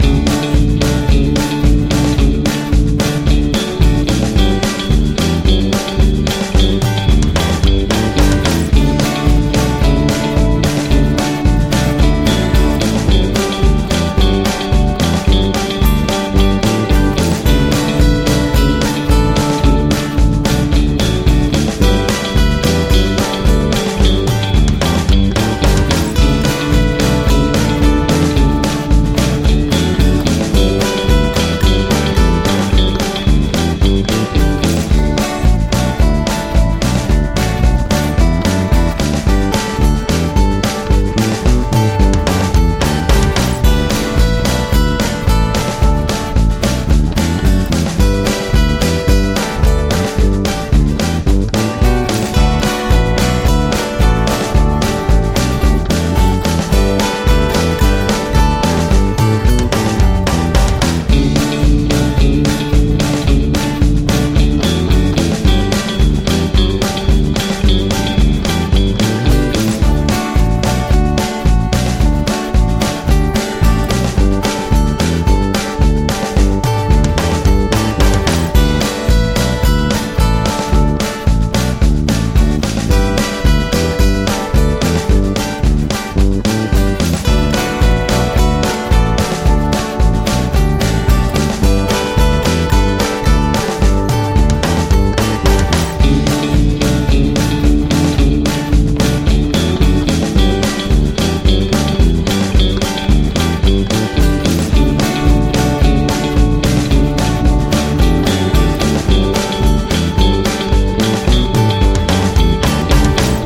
Веселая танцевальная музыка Дикого запада